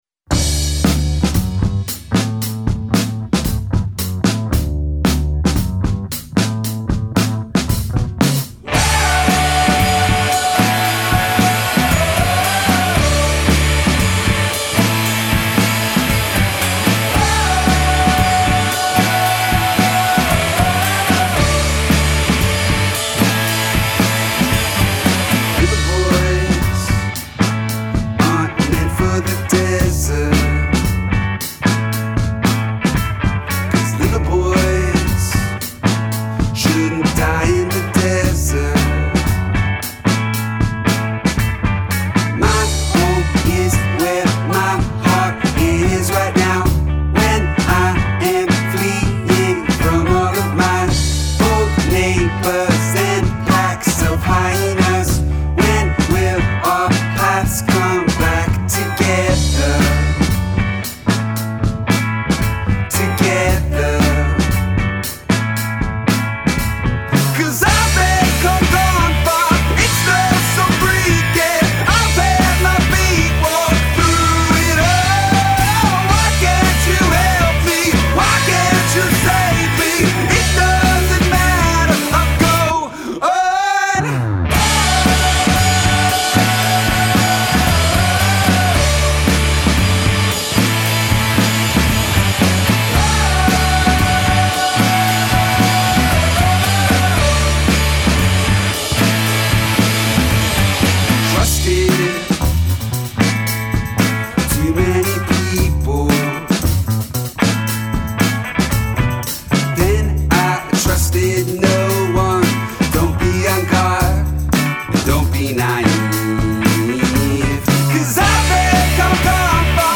Seattle power pop players
playfully anthemic